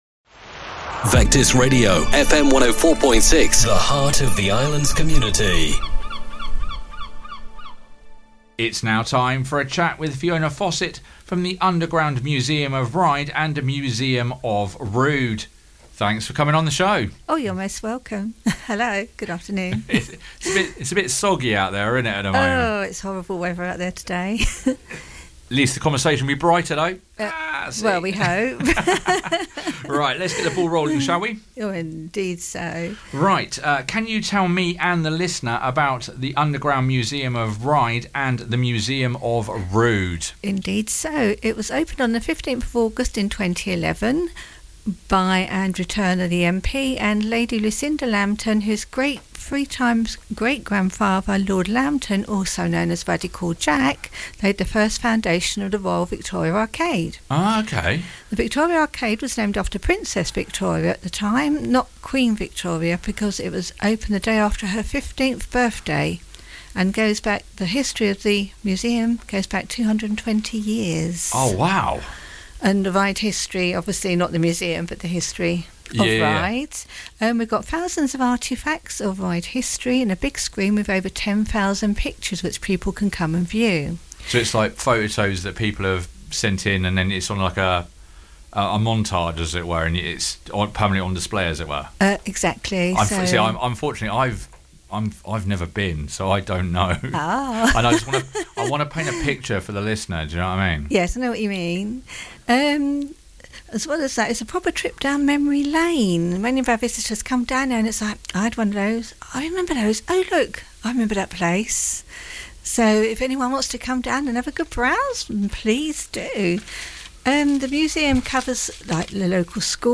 If you missed the original broadcast here is the podcasted version of the chat